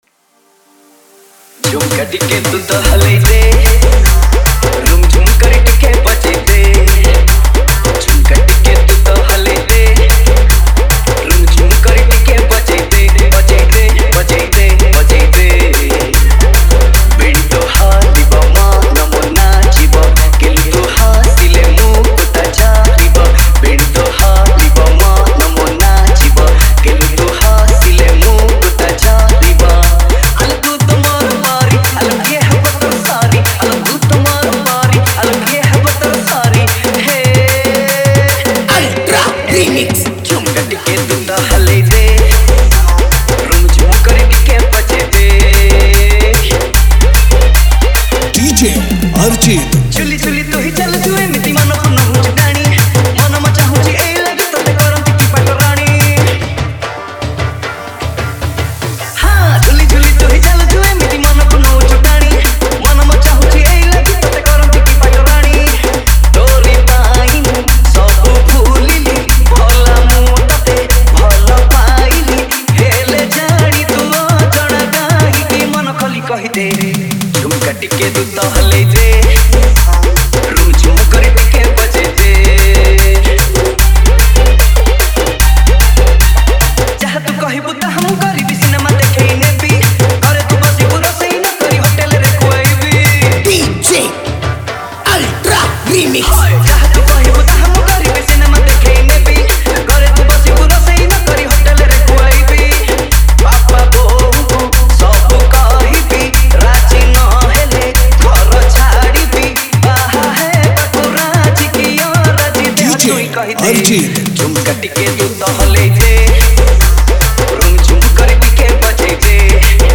• Category: SINGLE REMIX